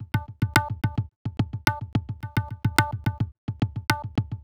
IND. TABLA-R.wav